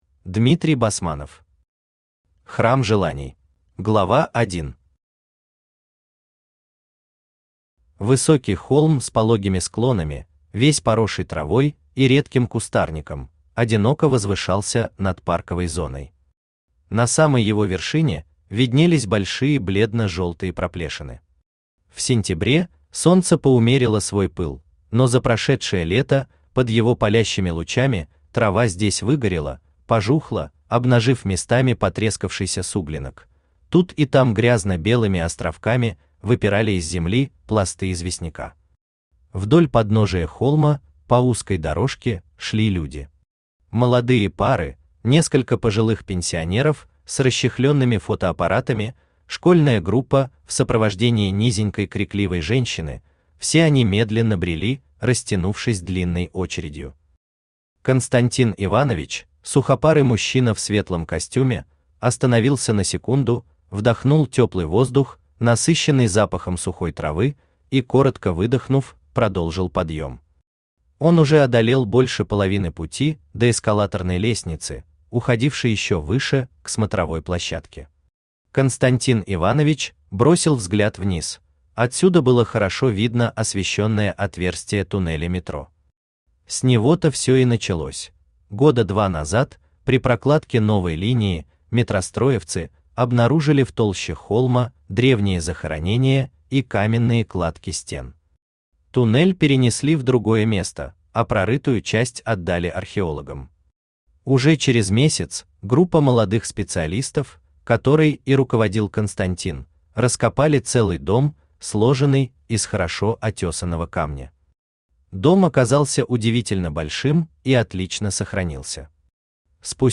Aудиокнига Храм желаний Автор Дмитрий Викторович Басманов Читает аудиокнигу Авточтец ЛитРес.